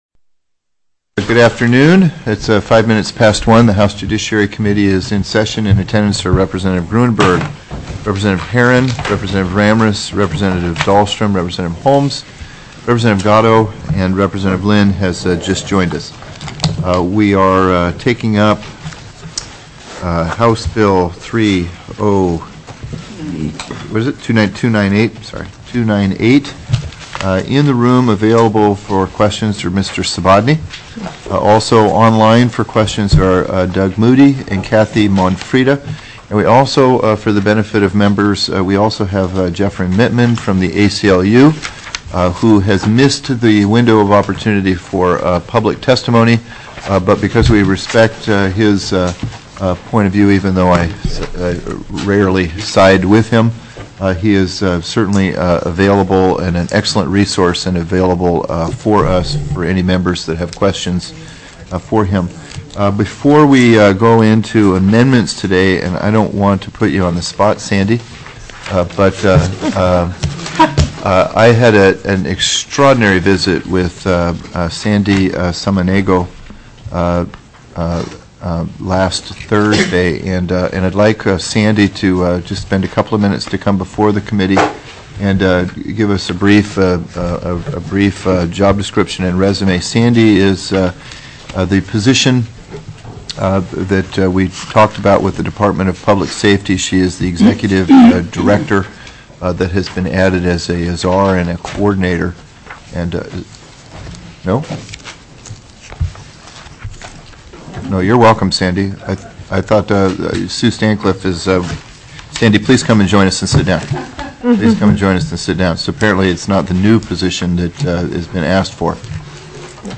02/01/2010 01:00 PM House JUDICIARY
01/25/10 (H) JUD AT 1:00 PM CAPITOL 120